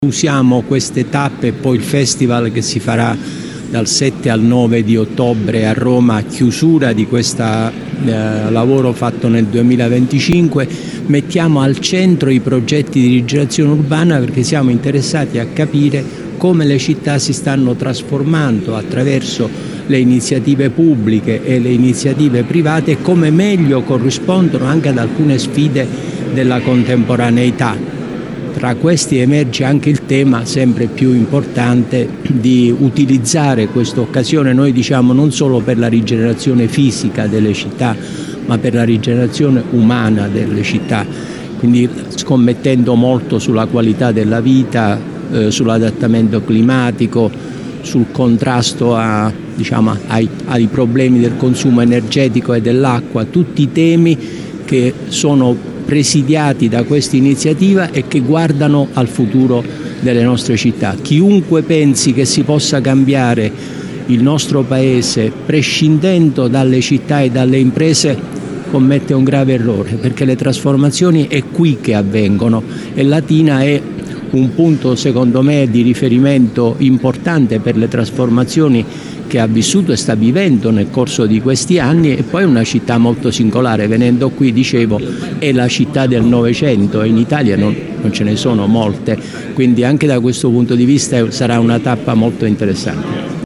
La tappa di Latina che andrà avanti per tutta la giornata è iniziata alle 10 al Museo Cambellotti.